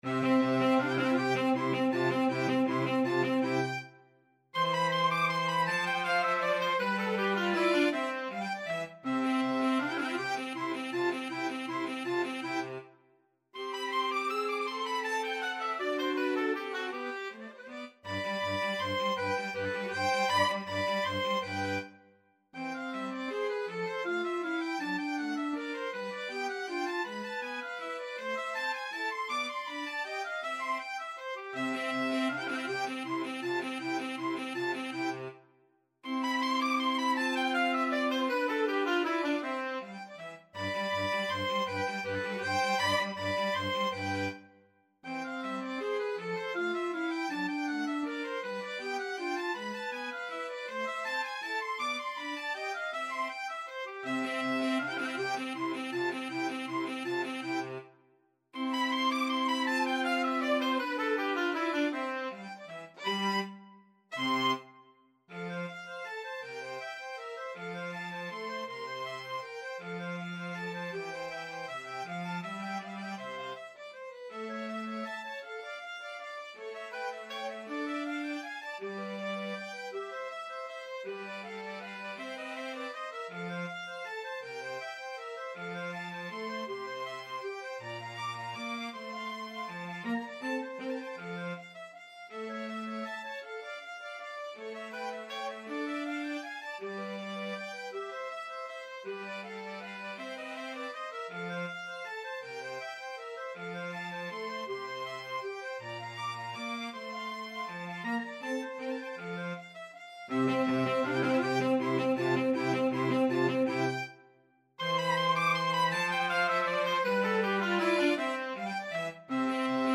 Oboe
Clarinet
Bass ClarinetBassoon
3/4 (View more 3/4 Music)
Allegro vivace =160 (View more music marked Allegro)